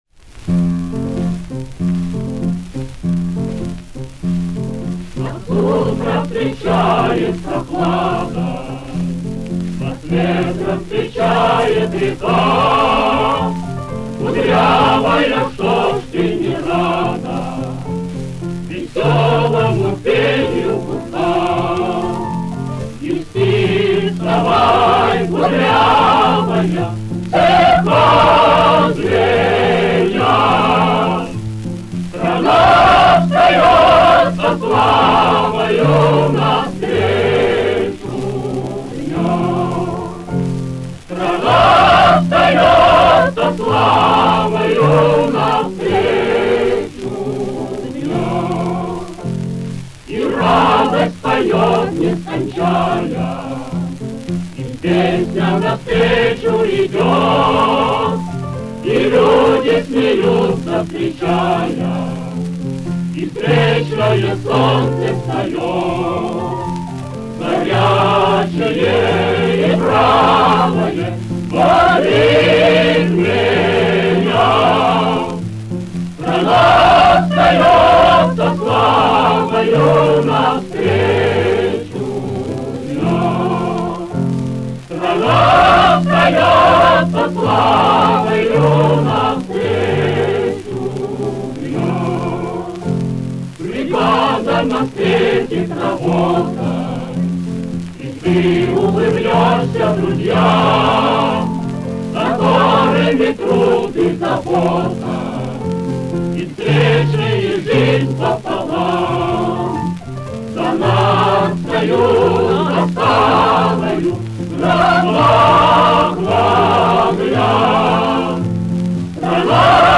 Редкая запись самодеятельного коллектива Ленинграда